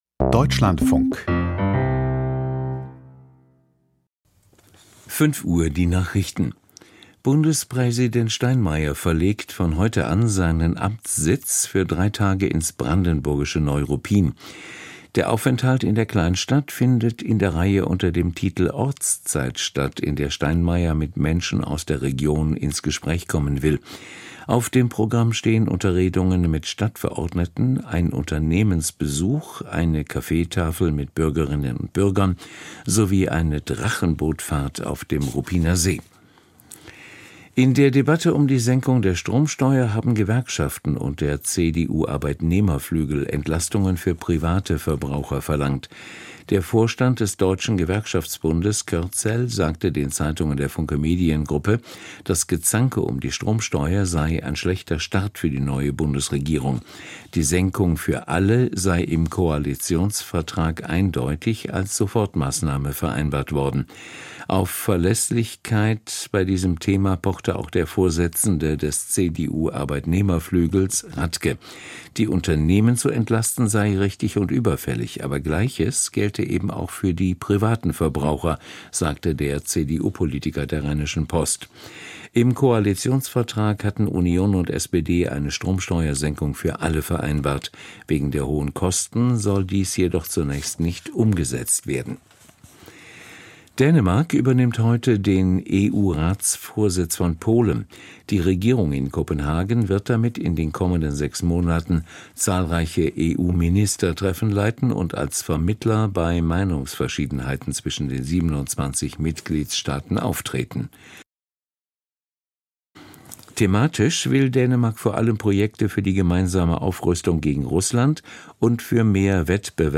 Die Nachrichten vom 01.07.2025, 05:00 Uhr